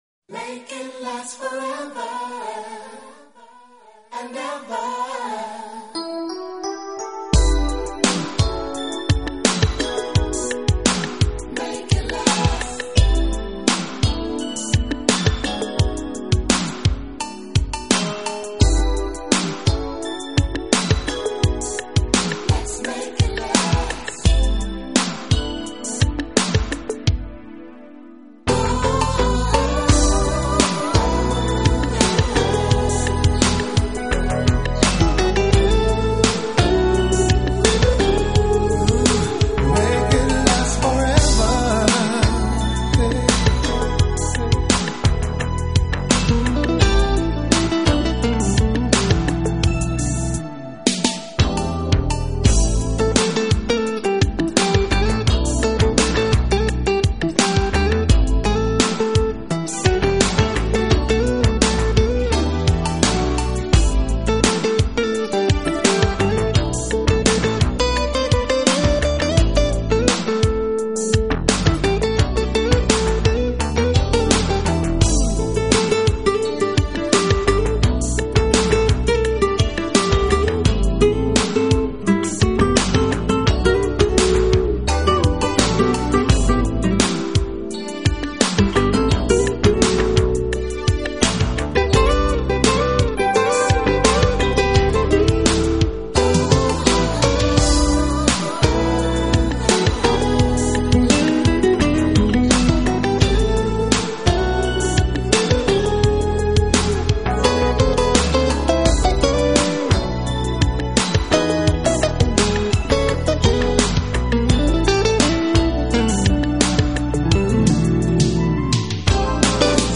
爵士吉他